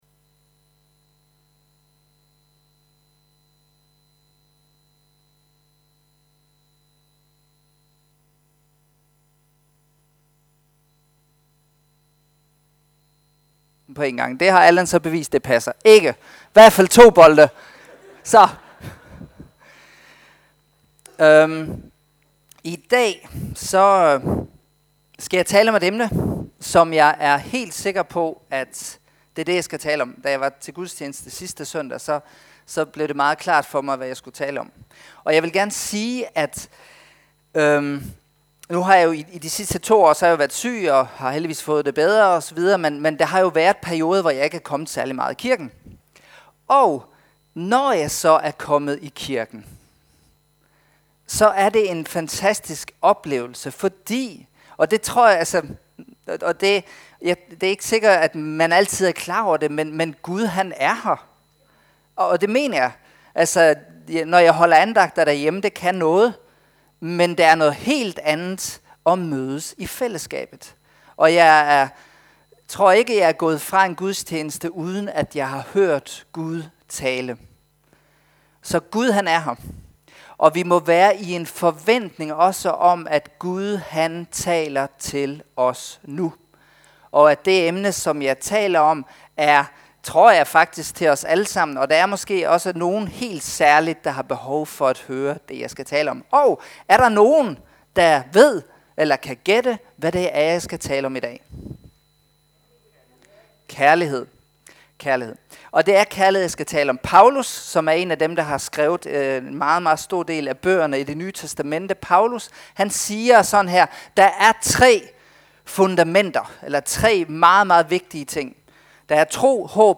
Prædikener fra Tønder Frikirke
Service Type: Nadver Gudstjeneste